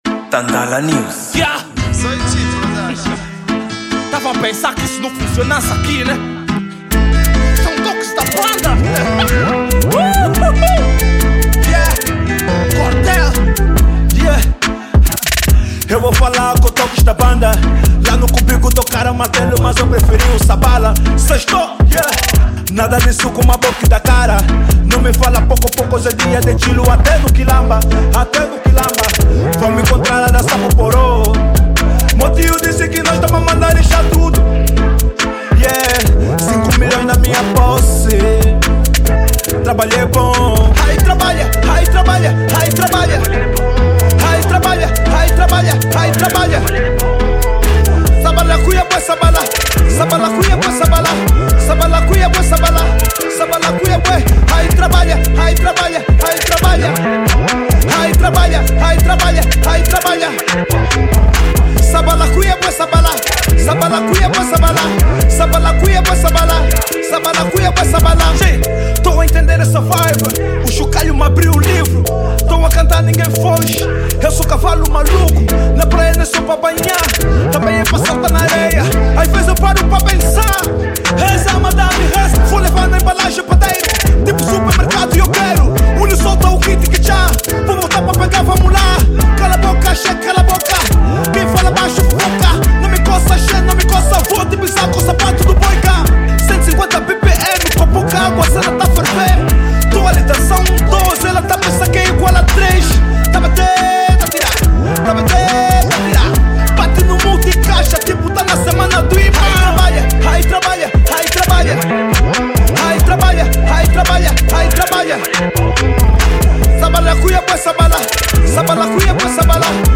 Gênero: Rap/Drill